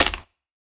Minecraft Version Minecraft Version 1.21.5 Latest Release | Latest Snapshot 1.21.5 / assets / minecraft / sounds / block / mangrove_roots / step3.ogg Compare With Compare With Latest Release | Latest Snapshot
step3.ogg